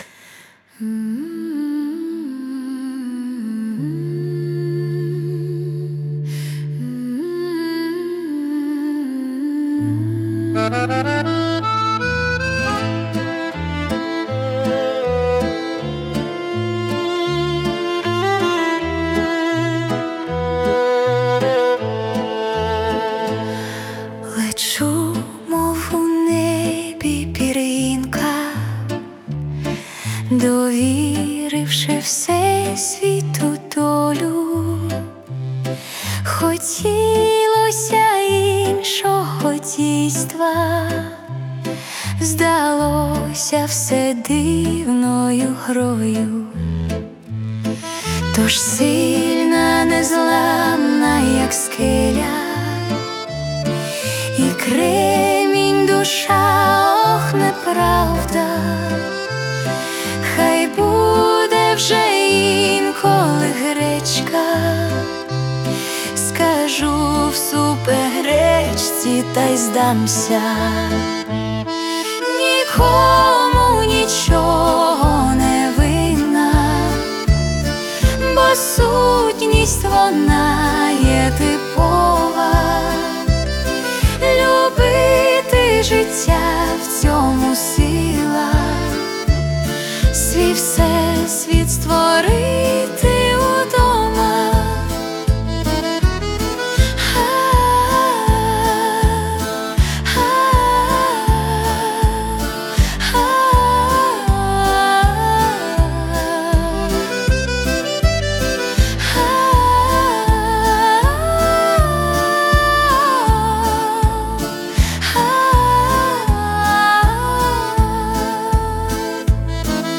Музична композиція створена за допомогою SUNO AI
СТИЛЬОВІ ЖАНРИ: Ліричний